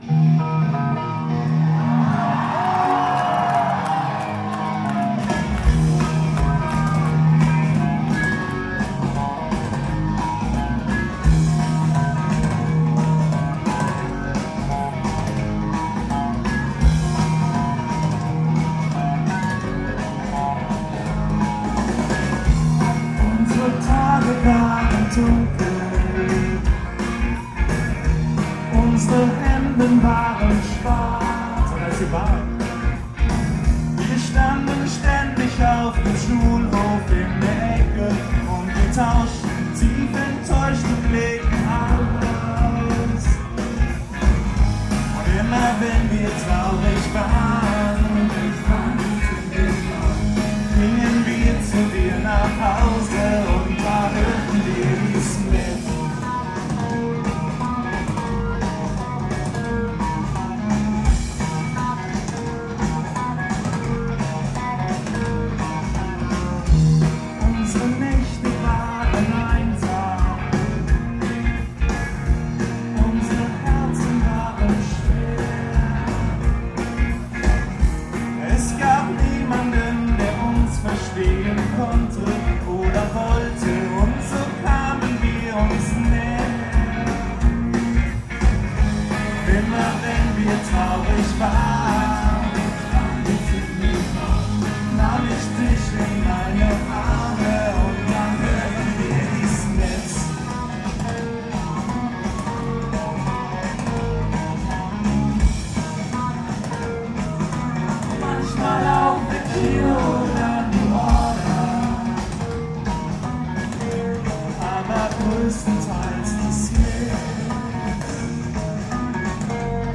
Aufnahmegerät: Sharp IM-DR420H (Mono-Modus)
Mikrofon: Sony ECM-T6 (Mono)